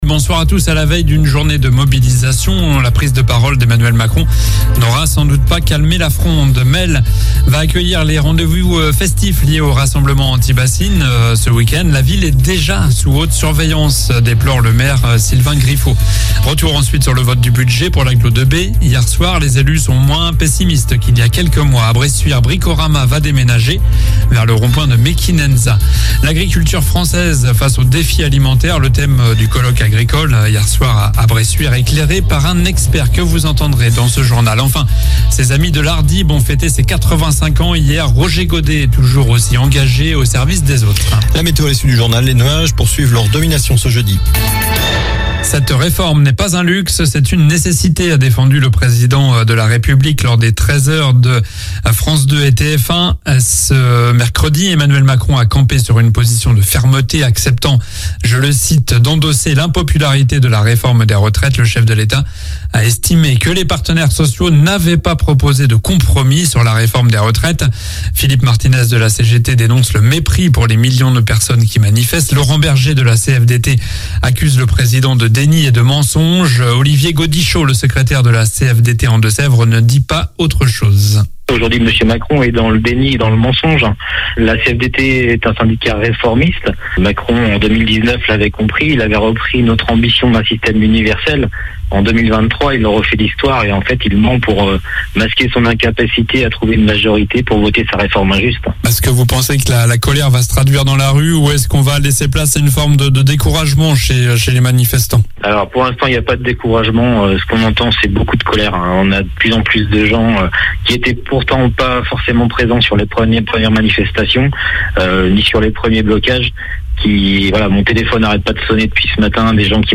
Journal du mercredi 22 mars (soir)